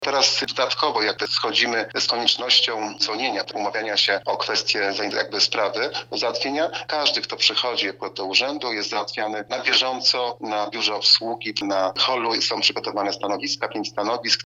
– Interesanci byli, i są obsługiwani na bieżąco – mówi burmistrz Jacek Tarnowski.